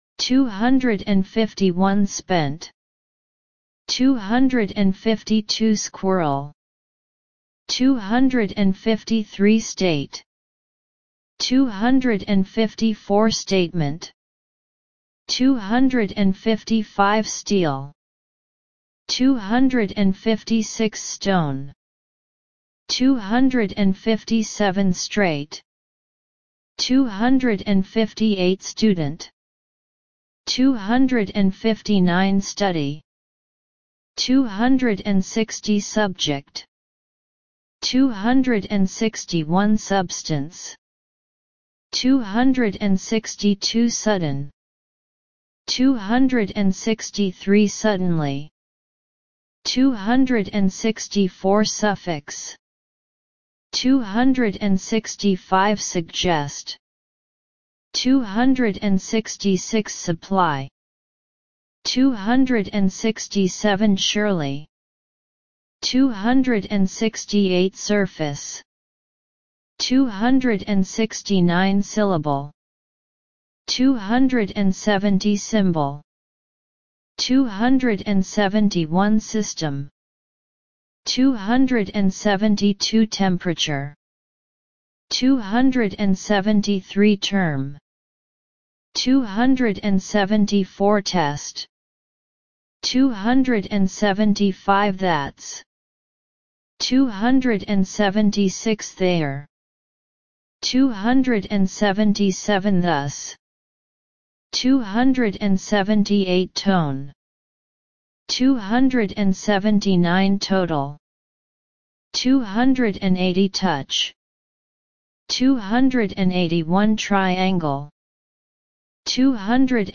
251 – 300 Listen and Repeat